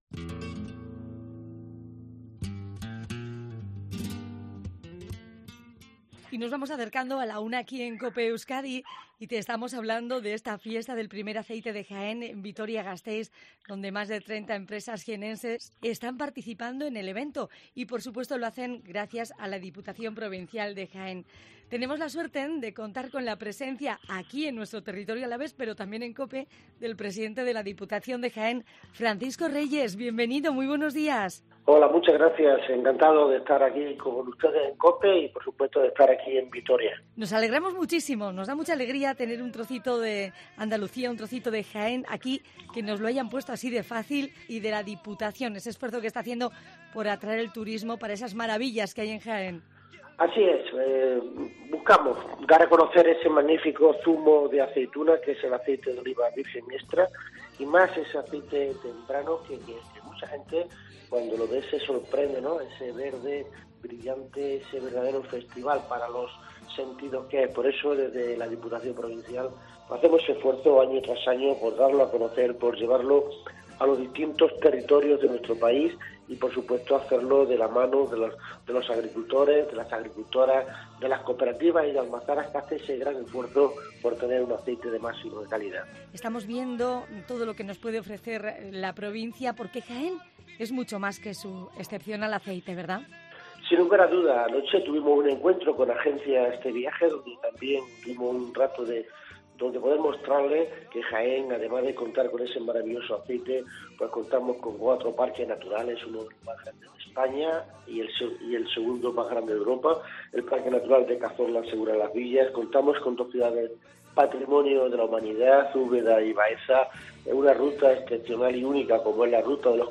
Entrevista a Francisco Reyes, Presidente de la Diputación de Jaén